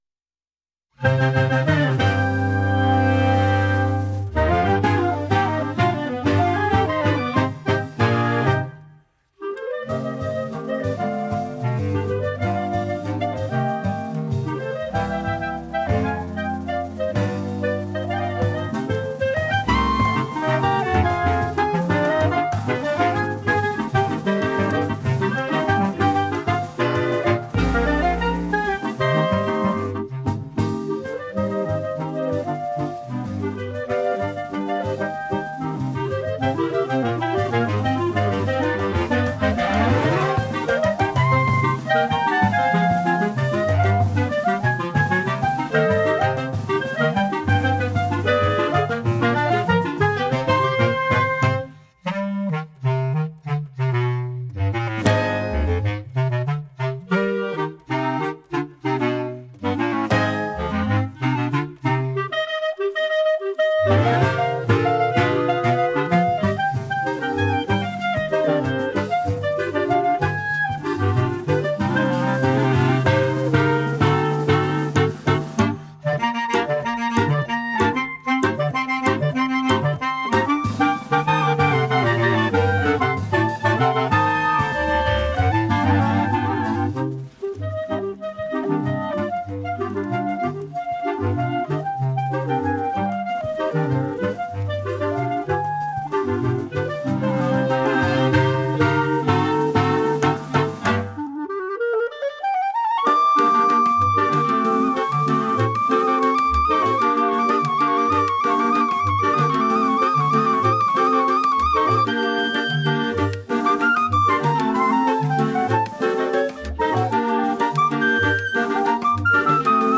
dc.subject.lembMúsica colombianaspa